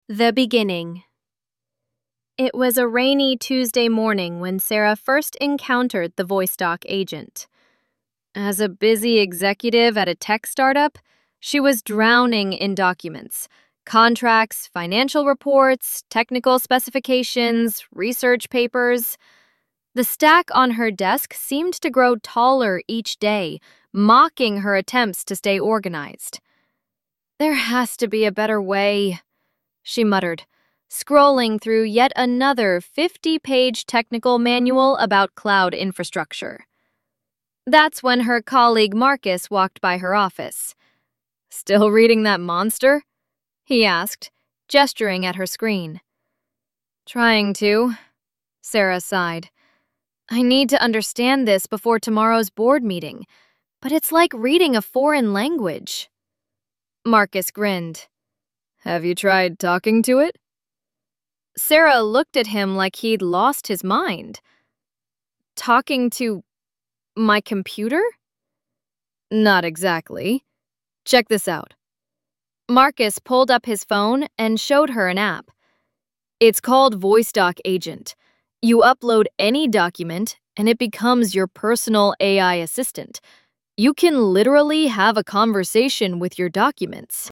This triggers the full two-stage Gemini pipeline — raw narration followed by emotion tagging — and streams the expressive audio response using ElevenLabs V3 voices.
ElevenLabs Narrative Voice
Below is a sample of the generated expressive narration audio:
sample-narrative-story-chapter-one.mp3